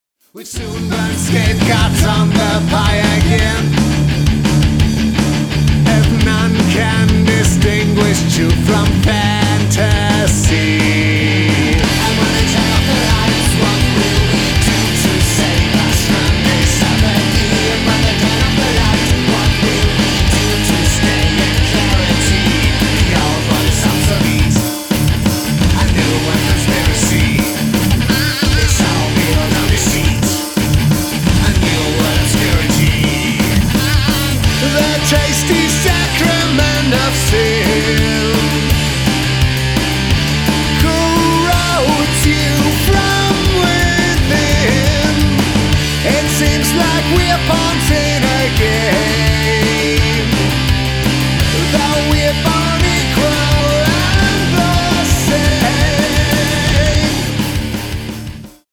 Metal
Vocals
Guitar, Bass
Drums